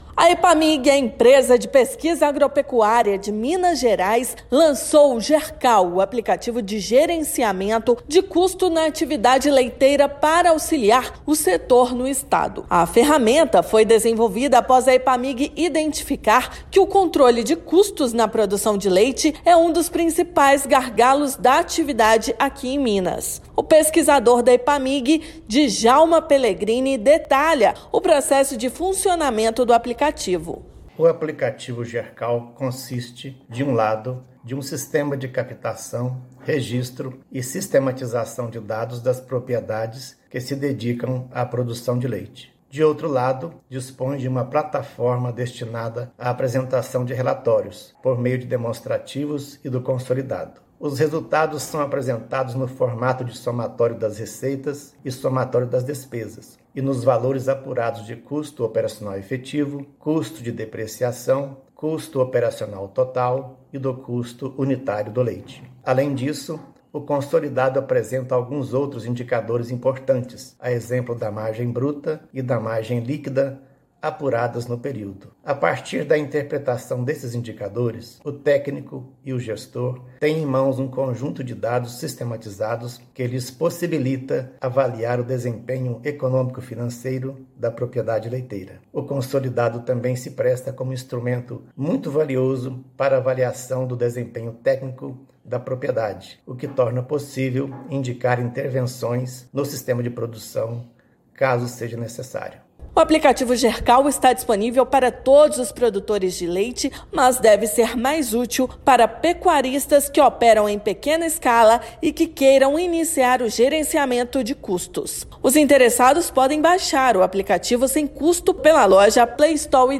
Gercal está disponível para dispositivos Android. Ouça matéria de rádio.